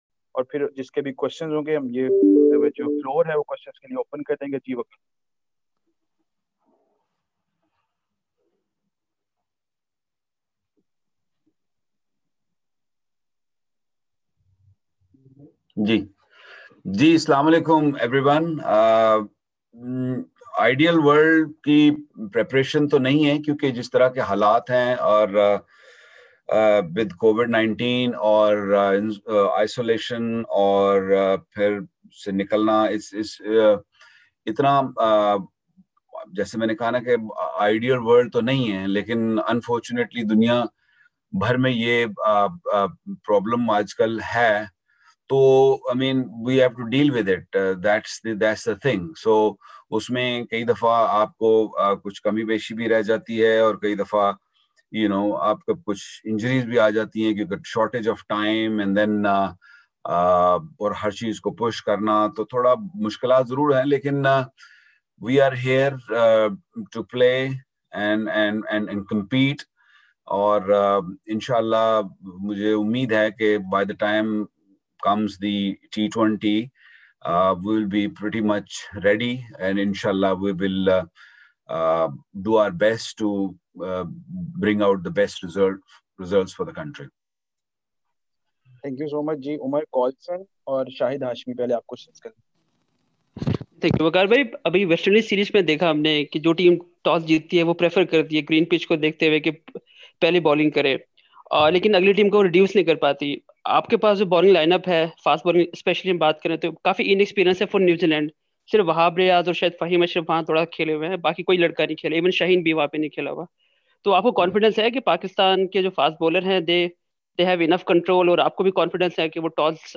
Waqar Younis, the bowling coach of the men’s national team, held a virtual media conference with the Pakistan media today.